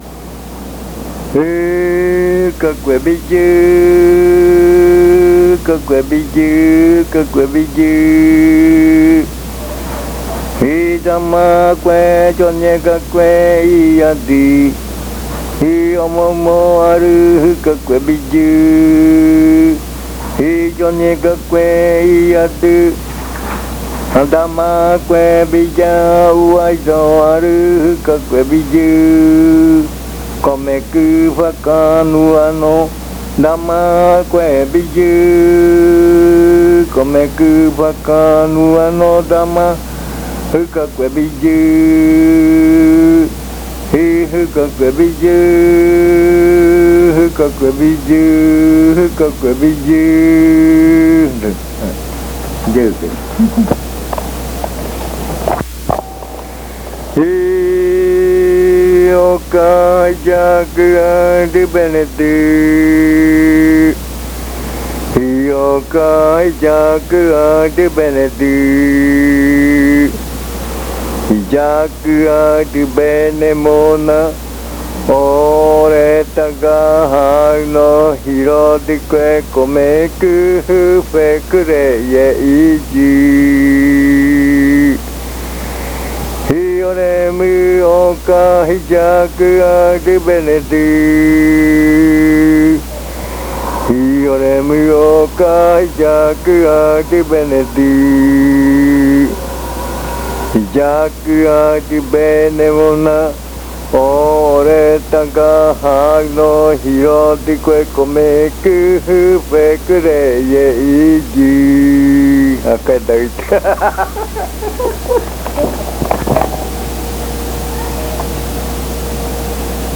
Leticia, Amazonas
Esta canción hablada (uuriya rua)
This spoken chant (uuriya rua)
This chant is part of the collection of chants from the Yuakɨ Murui-Muina (fruit ritual) of the Murui people